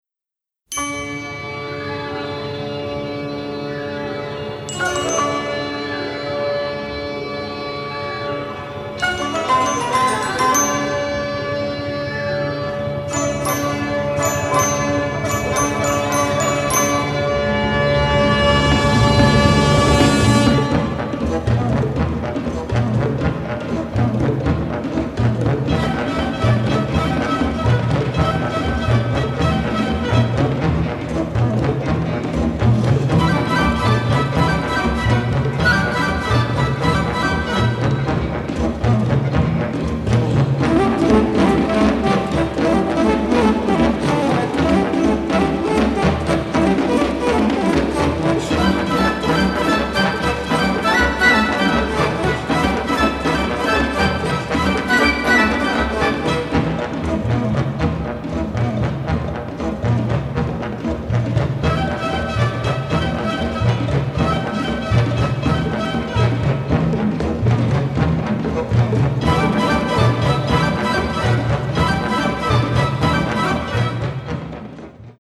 mostly in stereo, with some cues in mono